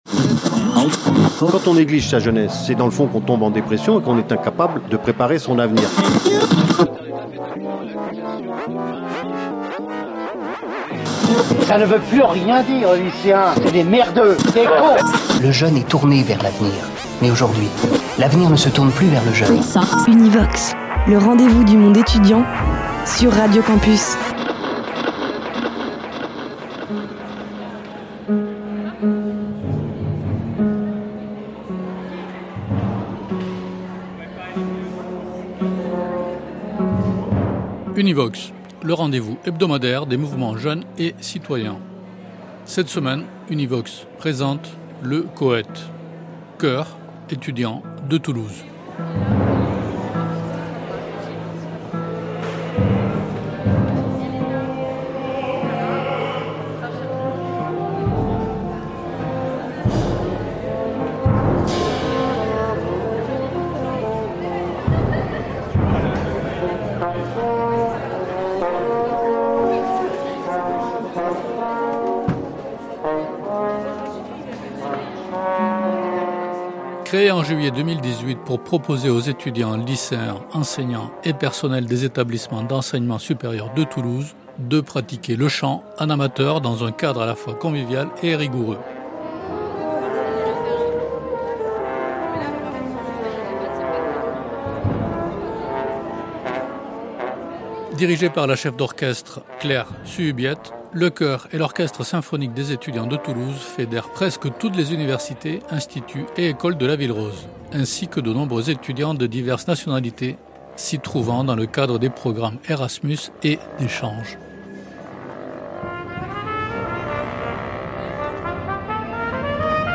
Le Choeur Étudiant de Toulouse | Univox, documentaire